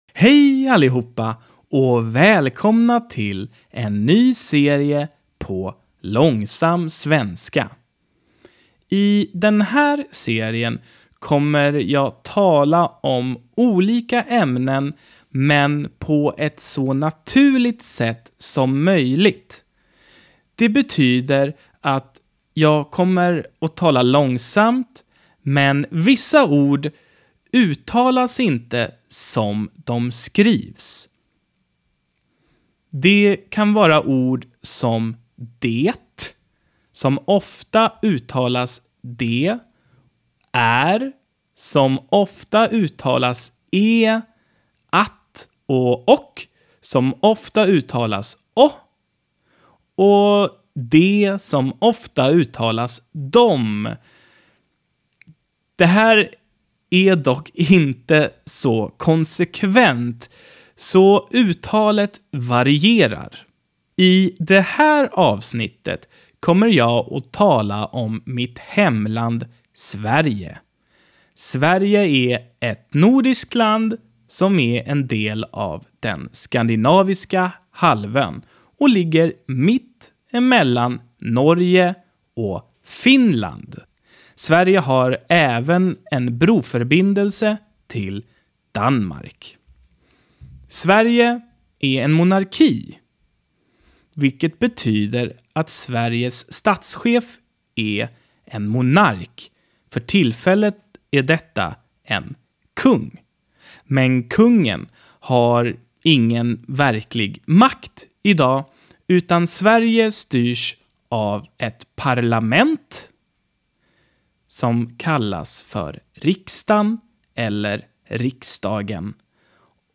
Langsames Schwedisch #1 - Om Sverige
Das heißt, dass ich langsam sprechen werde, aber manche Wörter werden nicht so ausgesprochen, wie sie geschrieben werden.
Das ist aber nicht so konsequent, also variiert die Aussprache.